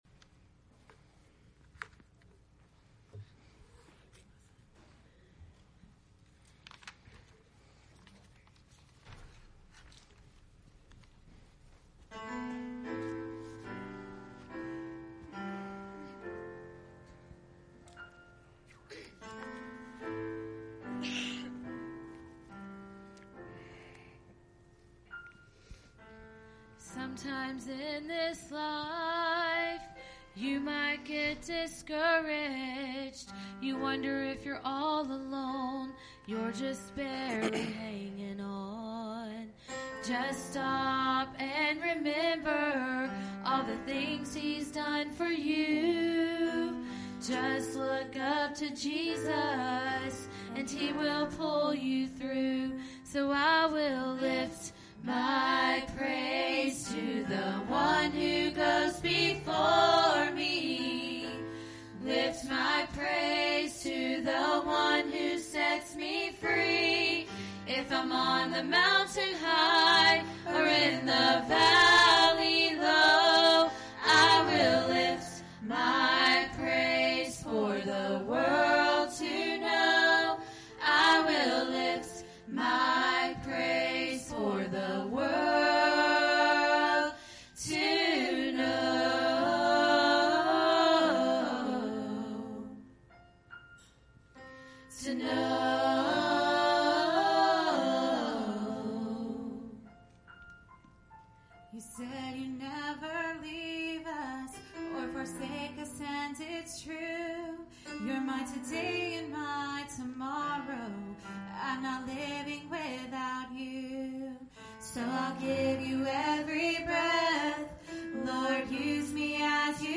Isaiah 61:1-3 Service Type: Sunday Evening « Testimony Service Defending Your Faith Pt 3